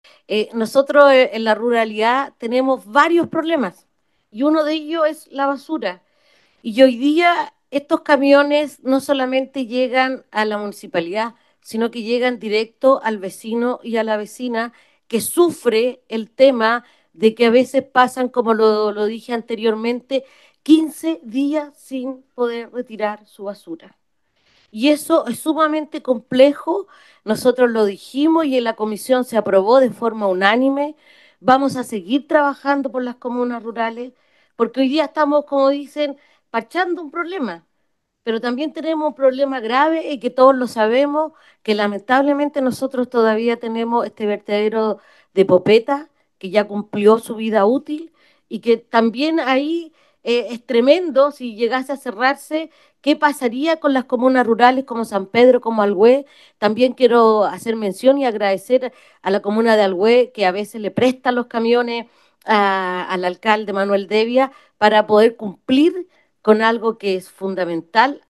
Entre las intervenciones realizadas, fue la consejera regional Cristina Soto Mesina, quien recordó que en dos oportunidades se abordó esta iniciativa en la comisión rural, para luego dar paso a su recomendación para su votación en sala y que al ser representante de una provincia como Melipilla, donde ella conoce de sus necesidades “Nosotros en la ruralidad tenemos varios problemas y uno de ellos es la basura y hoy día estos camiones no solamente llegan a la municipalidad, sino que llegan directo al vecino y a la vecina que sufre el tema”, además agradeció y destaco a la comuna de Alhué por apoyar a San Pedro, comuna que ha facilitado uno de sus camiones al alcalde Manuel Devia.